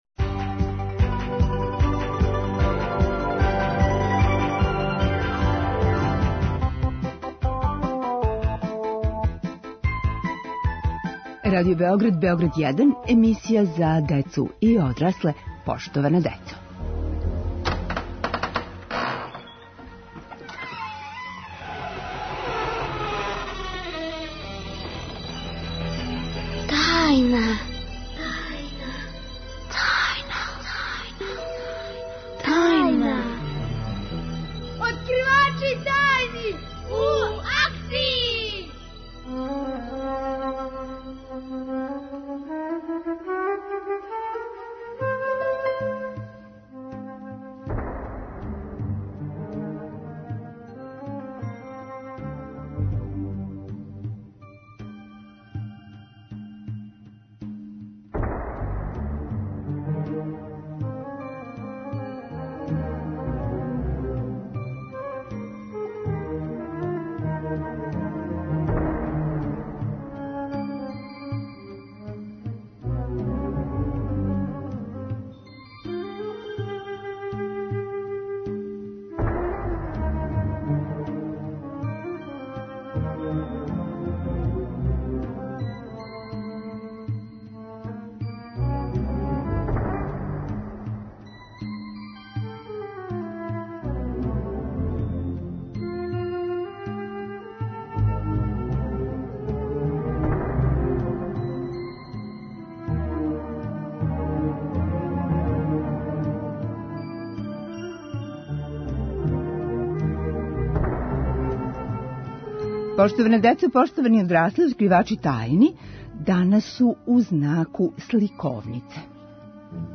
Гости: деца и младе ауторке наградјене сликовнице Облак